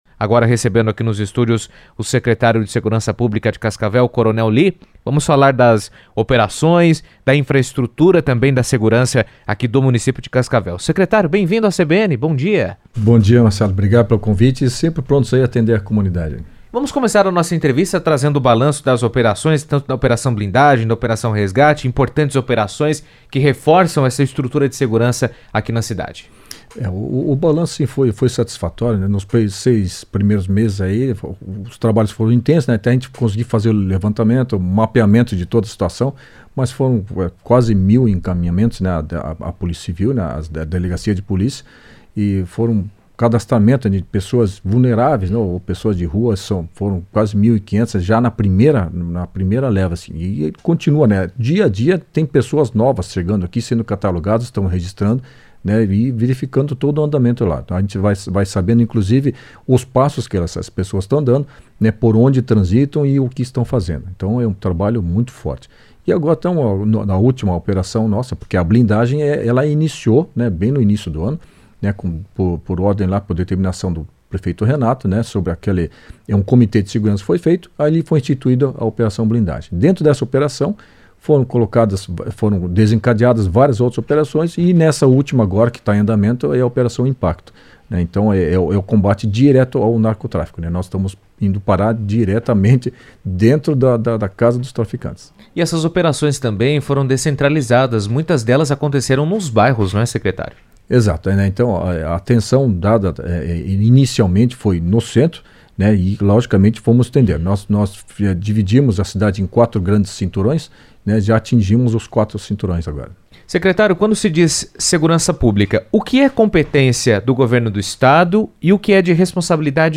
O secretário coronel Lee comentou os resultados em entrevista à CBN Cascavel.